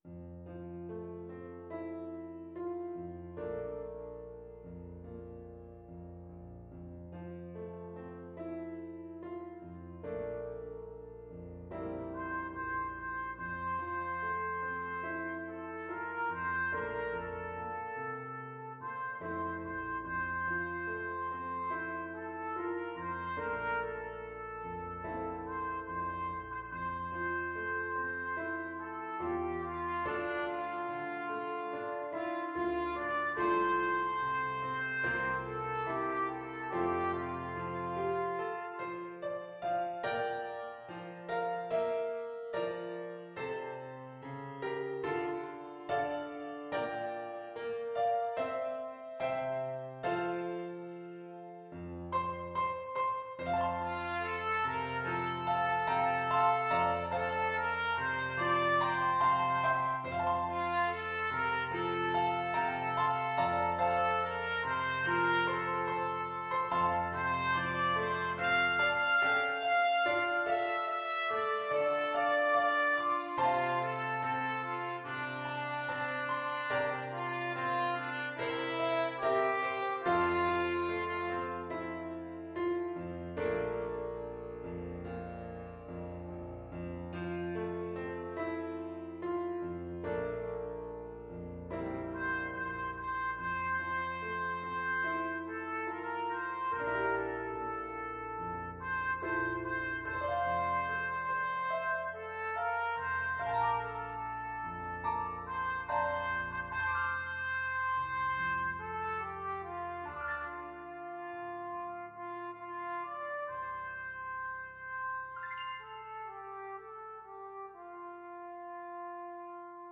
Trumpet
F major (Sounding Pitch) G major (Trumpet in Bb) (View more F major Music for Trumpet )
Molto espressivo =c.72
4/4 (View more 4/4 Music)
D5-F6
Classical (View more Classical Trumpet Music)